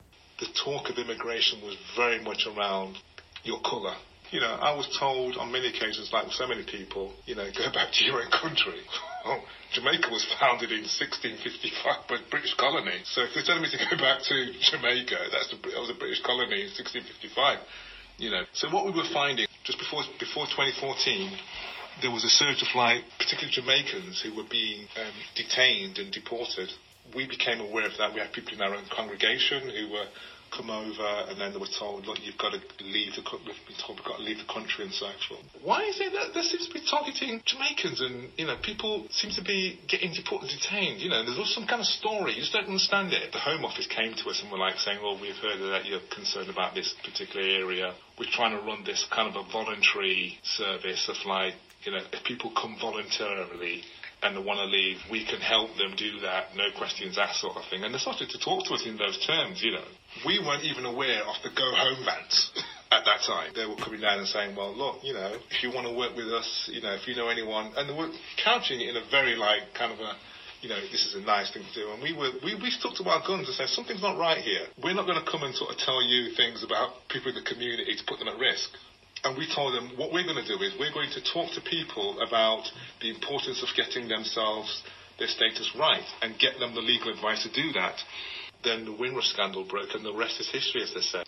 interviewee
This oral history excerpt has been drawn from the three-year AHRC-funded project ‘The Windrush Scandal in a Transnational and Commonwealth Context’.